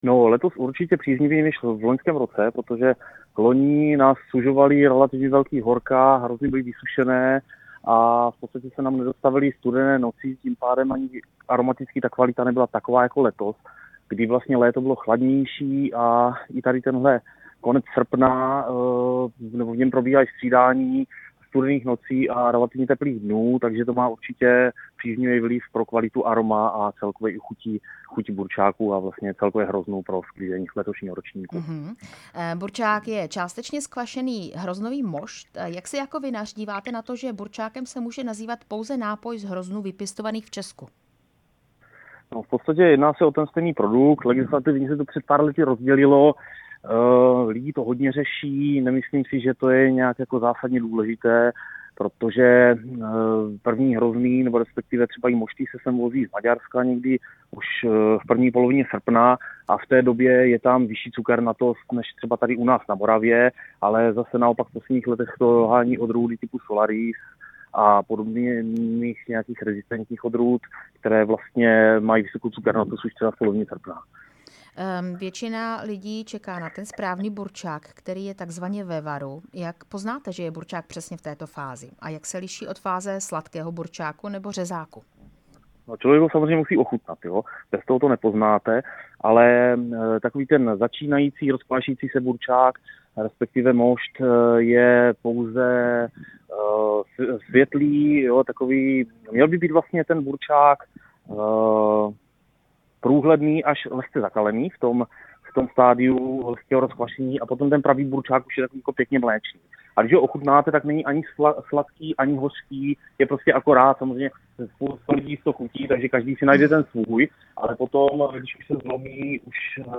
Rozhovor s vinařem